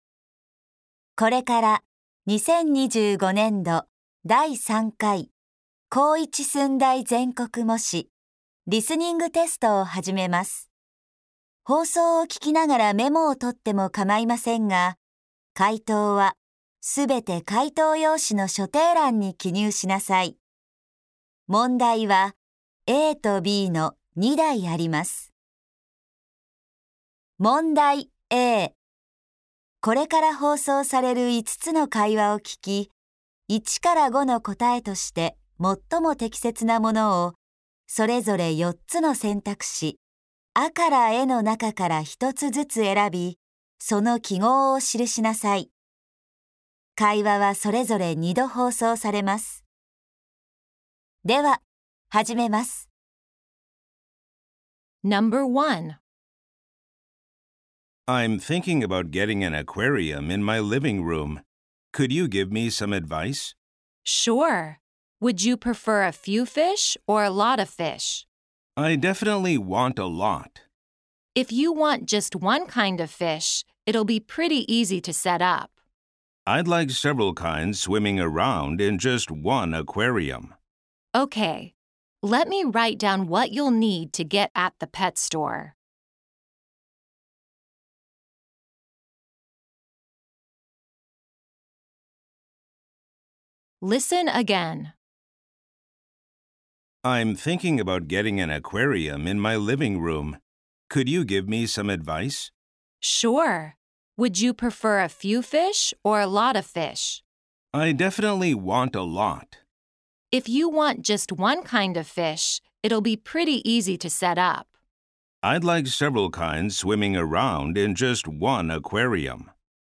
リスニング音声の再生直後に下記の説明が流れますので、その間に聞き取りやすい音量に調節を行ってください。